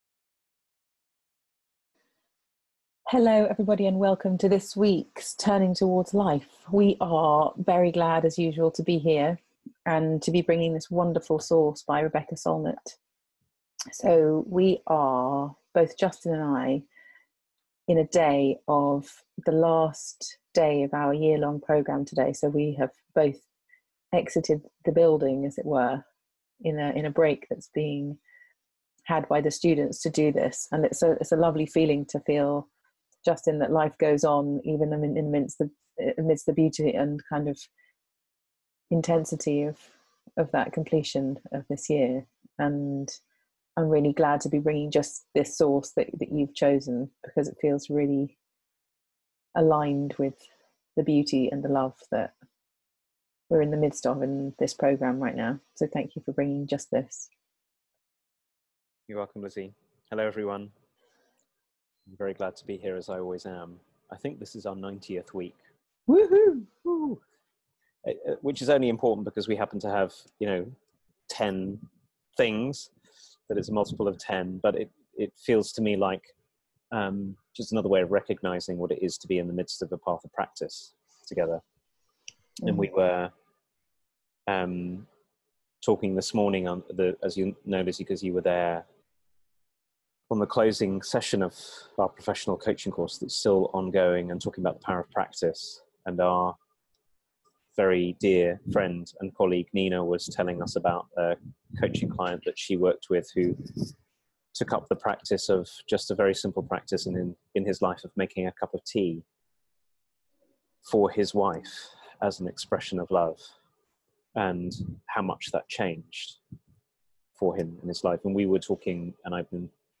It launches us into conversation about the difference between feeling love and loving. We explore how practicing love without demanding that we feel a particular way, or get something particular in return, opens up a whole world.